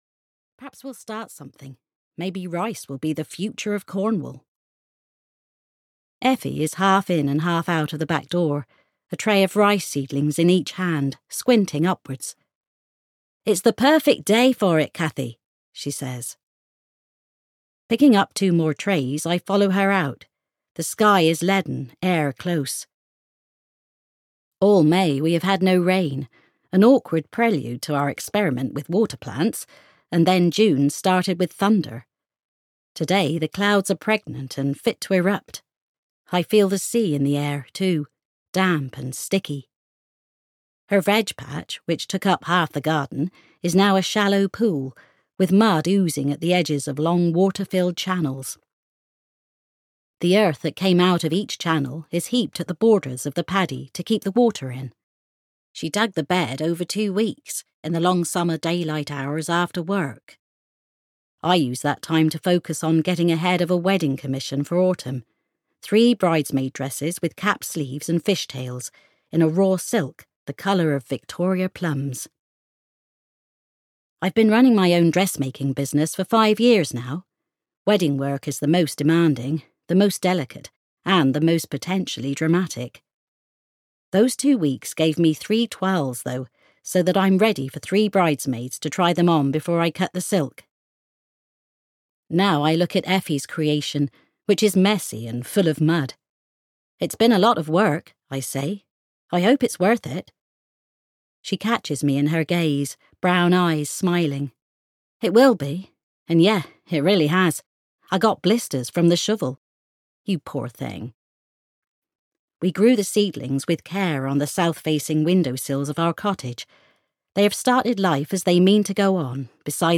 The Fish (EN) audiokniha
Ukázka z knihy